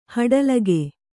♪ haḍalage